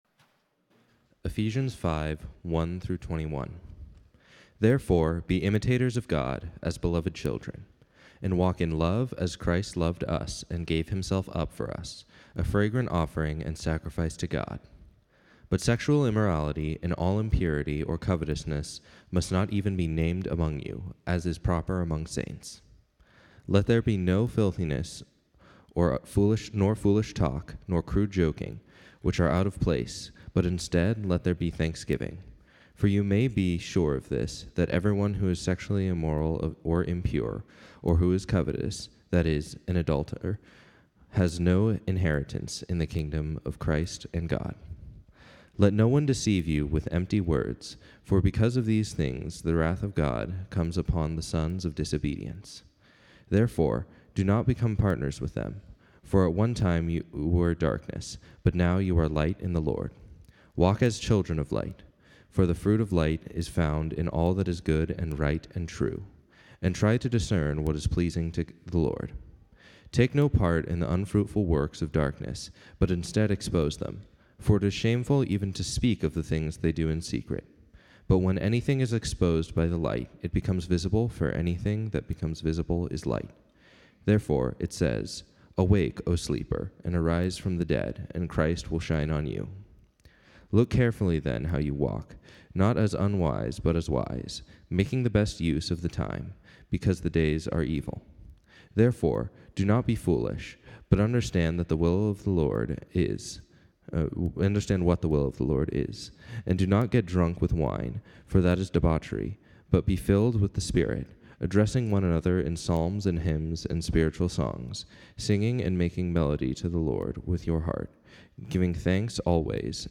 A message from the series "Use It Well."